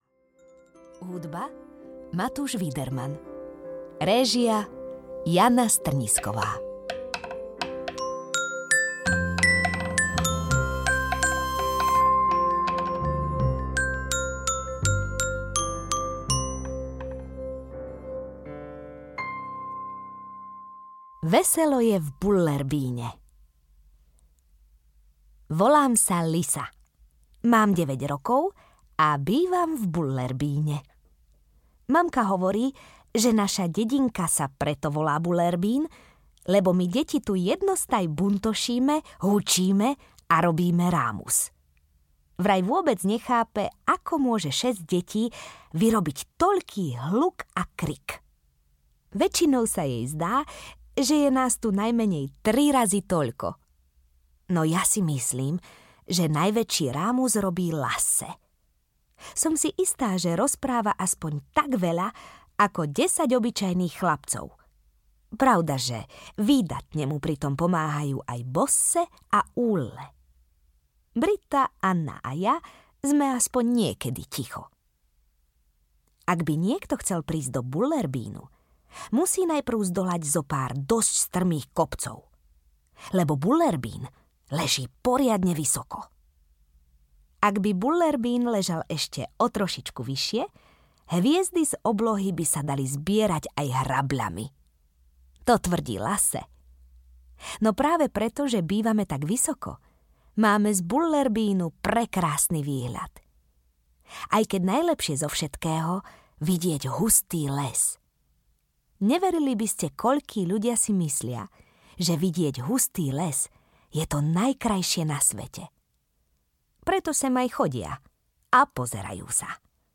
Veselo je v Bullerbyne audiokniha
Ukázka z knihy
• InterpretTáňa Pauhofová